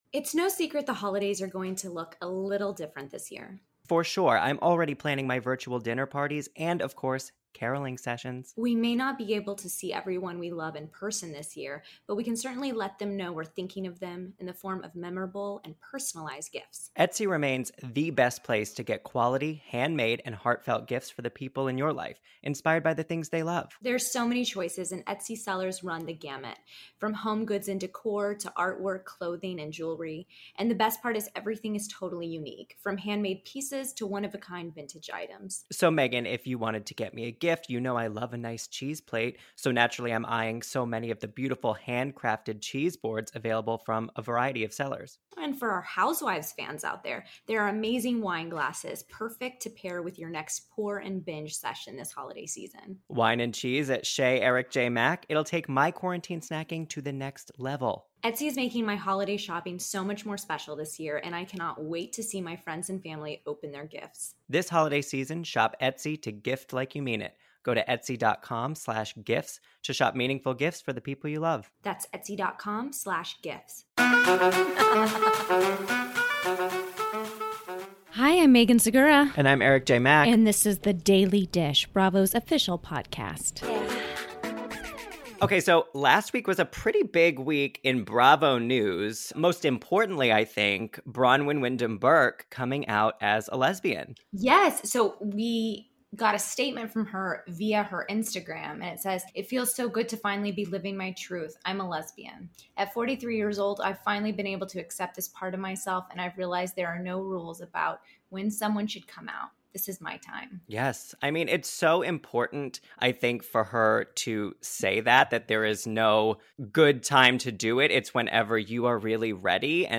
Plus, Southern Charm’s Madison LeCroy calls in to discuss the current state of her relationship with Austen Kroll, what’s happened with John Pri...